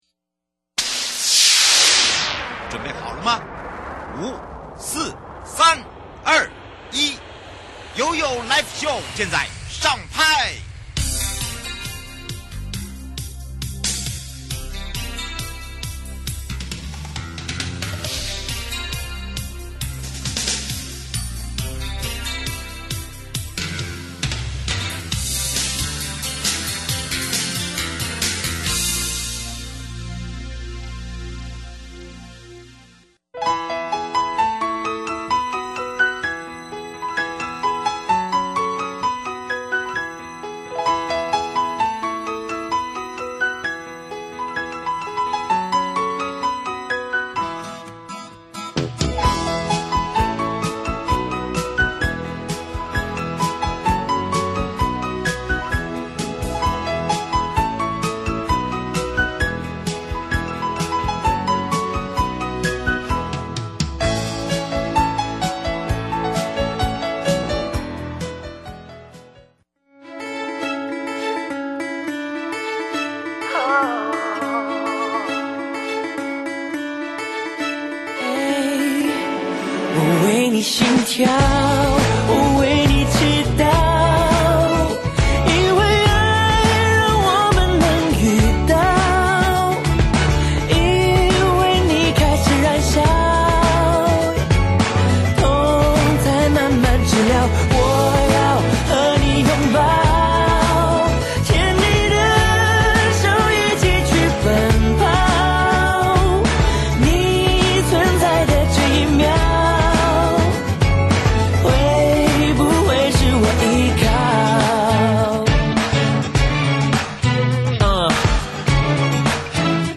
受訪者： 1. 台北地檢 王銘裕主任檢察官 2. 法務部 蔡清祥部長 節目內容： 1.